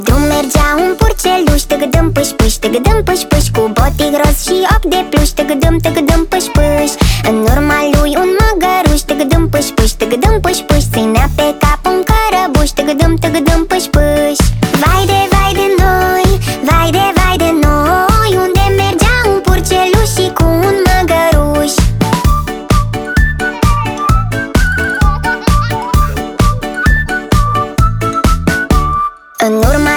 Children's Music